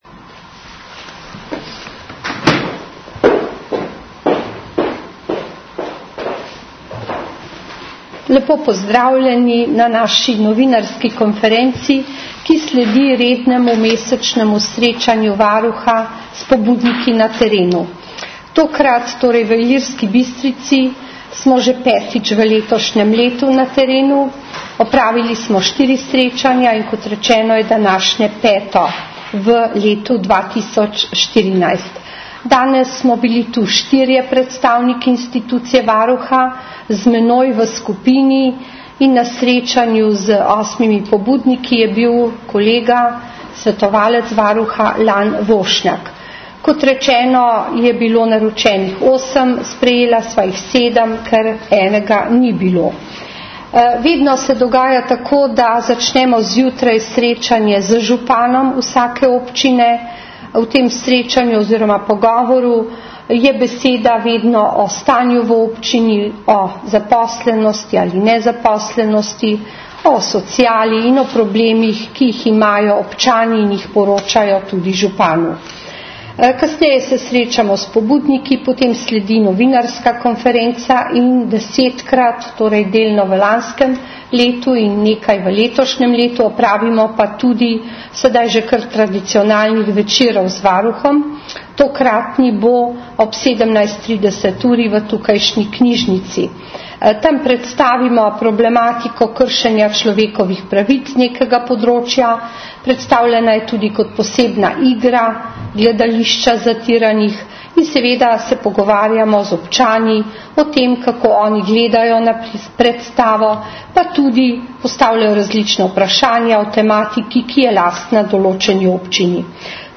Svoje ugotovitve je predstavila na krajši novinarski konferenci.
Zvočni posnetek novinarske konference lahko poslušate tukaj.